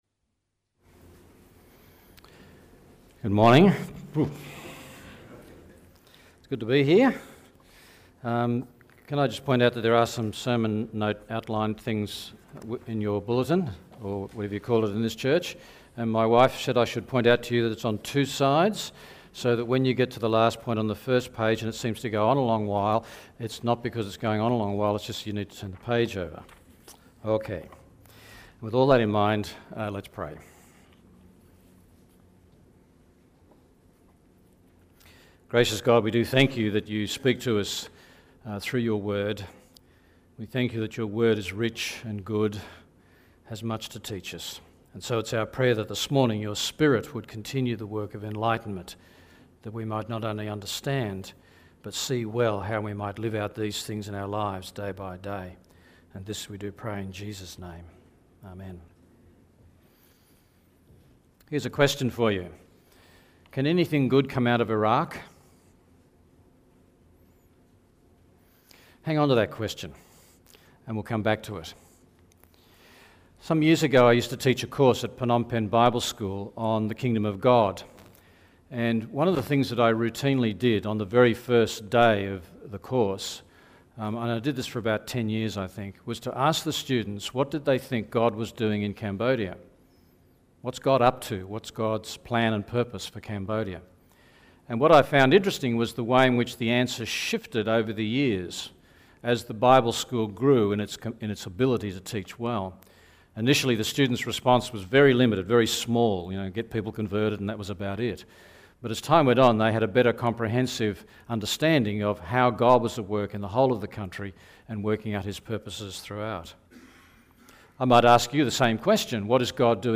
The Bible in 10 Easy Lessons Passage: Genesis 15:5-12, Colossians 1:15-20 Service Type: Sunday Morning